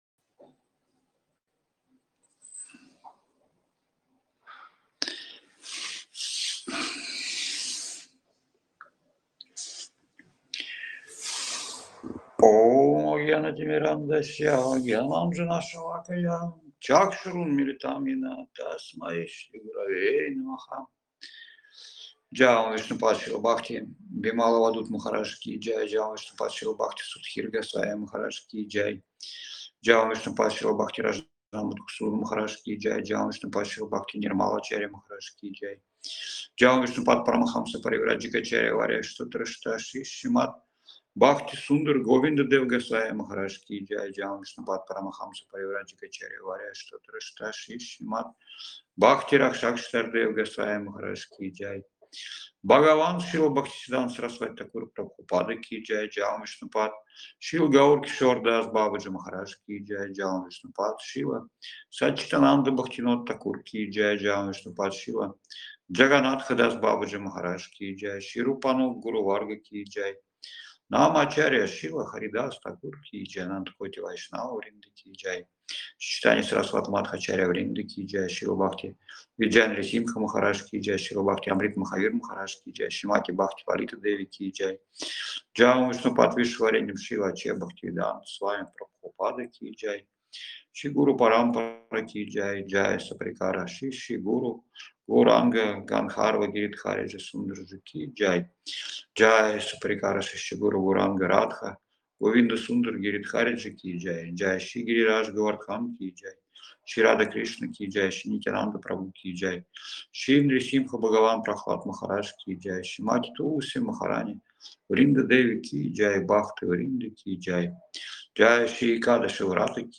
Чиангмай, Таиланд